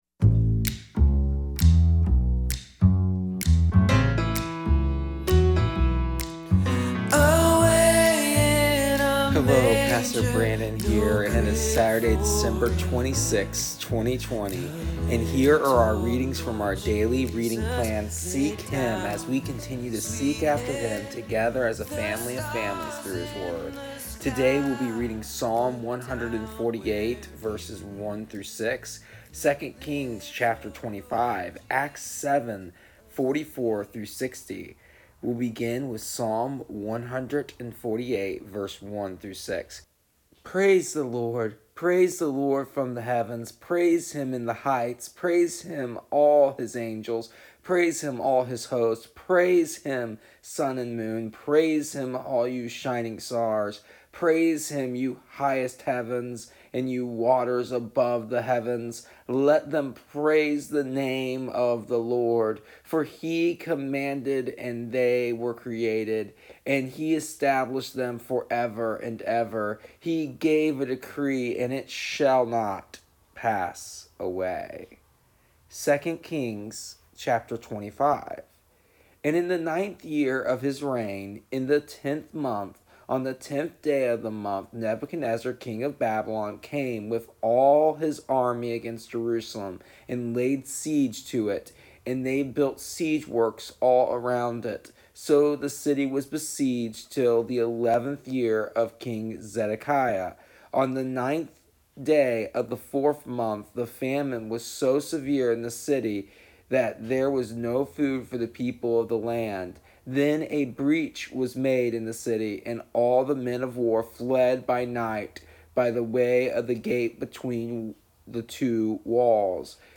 Here is the audio version of our daily readings from our daily reading plan Seek Him for December 26th, 2020, and do not fret do not fear.